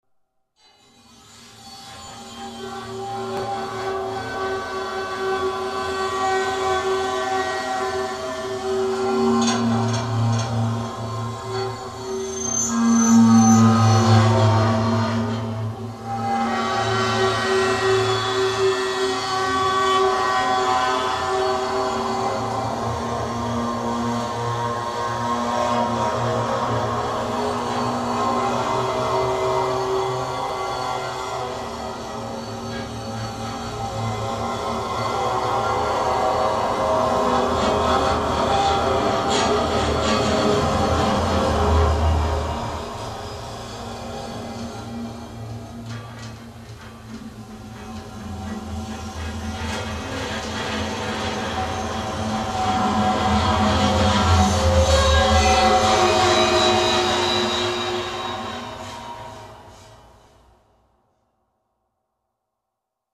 Clothes dryer with violin bow.
I placed two guitars on a clothes dryer, and i "played" it with a violin bow.
Then, i took part of this recording and i tried to process it with delay, reverberi, pitch shifer, etc, to obtain something like an "evolving pad":
clothes_dryer_Processed_1.mp3